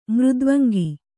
♪ mřdvangi